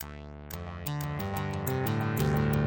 描述：用果味循环制作的低节奏的低音合成器延迟。
Tag: 90 bpm Chill Out Loops Bass Loops 459.55 KB wav Key : D